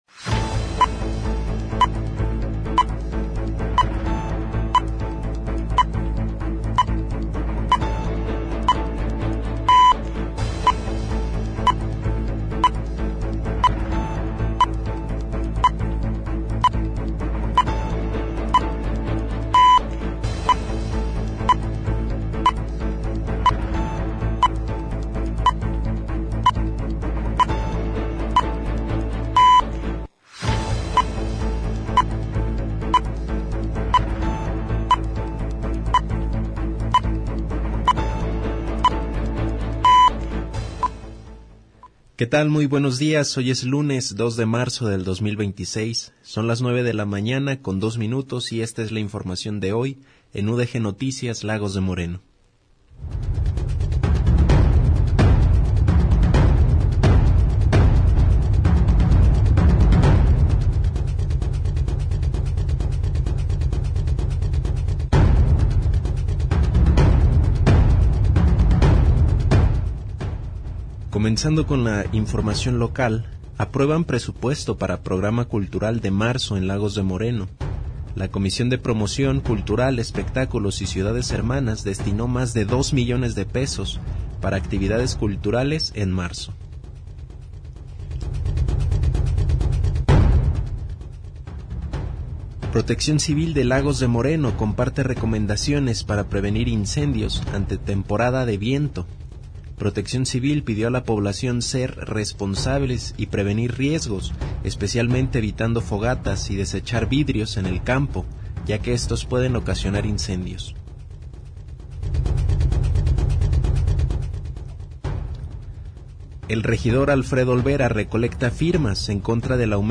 GÉNERO: Informativo